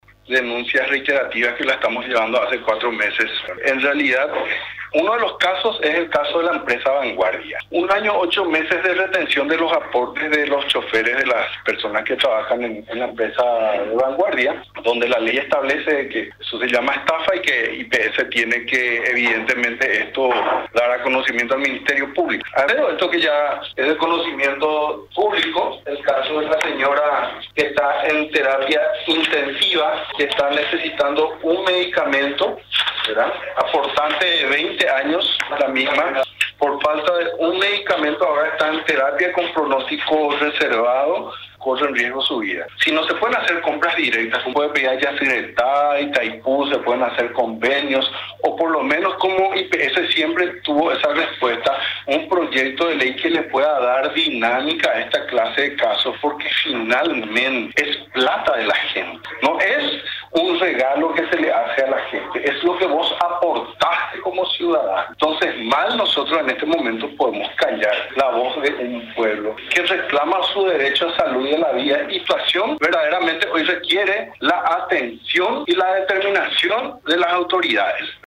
El Defensor del Pueblo Miguel Godoy, manifestó que hay personas que están aportando hace más de 20 años y cuando necesitan no cuentan con los mínimos medicamentos.